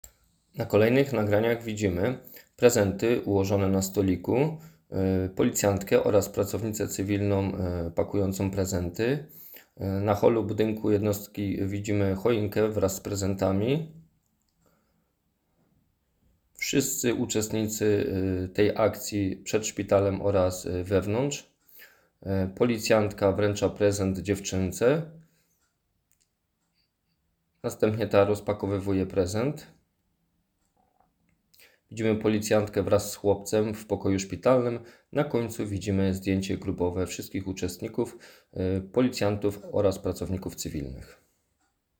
Nagranie audio Audiodeskrypcja-5.m4a